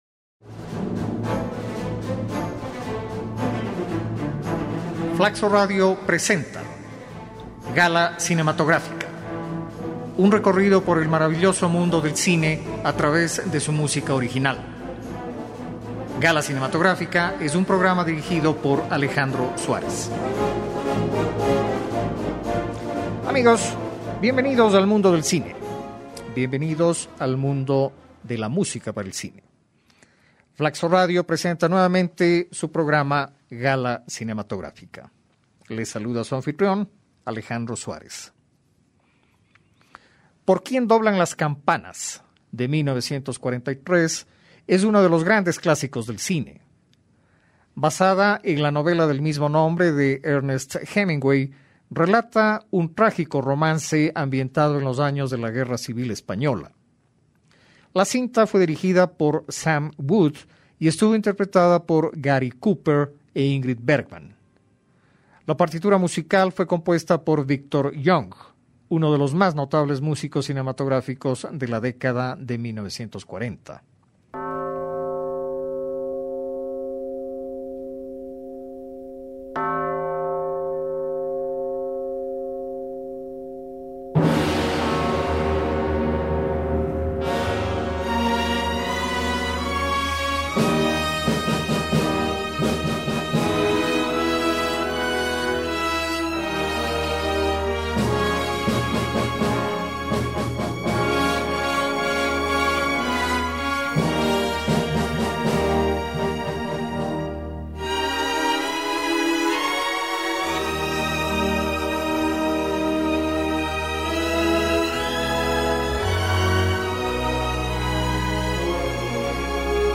algunos fragmentos de la entrañable partitura